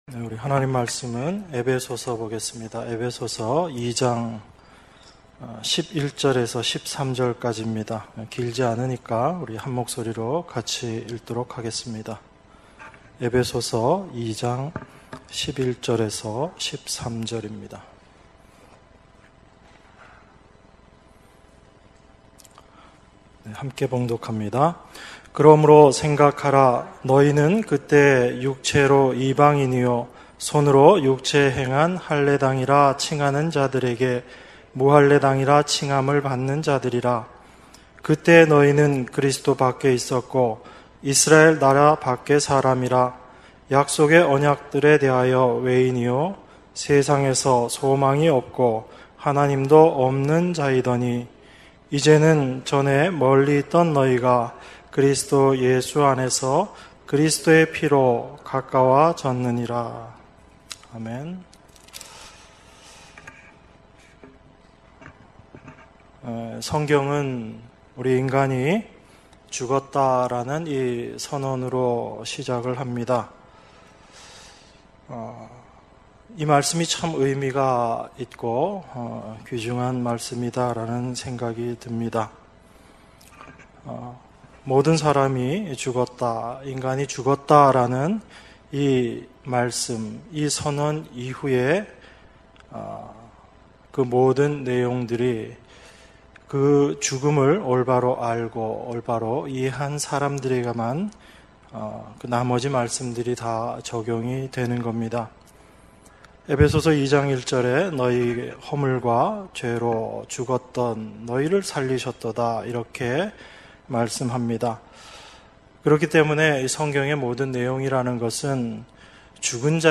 주일예배 - 에베소서 2장 11절~13절 주일2부